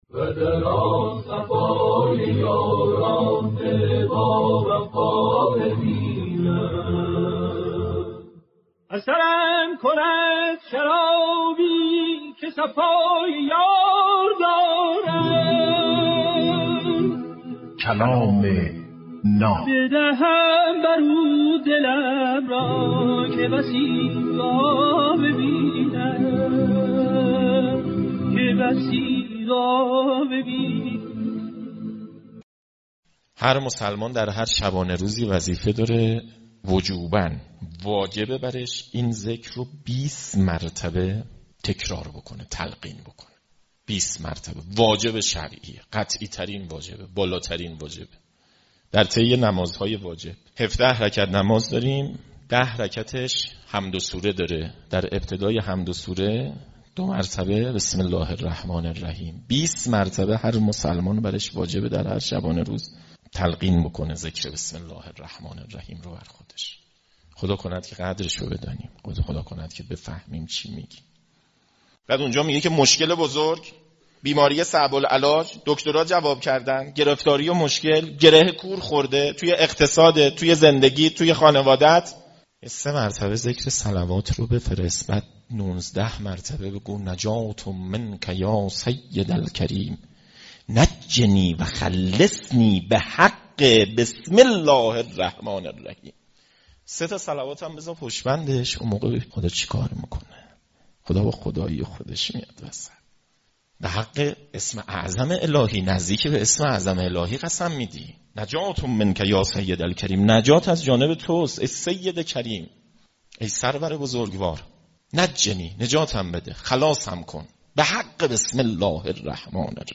کلام ناب برنامه ای از سخنان بزرگان است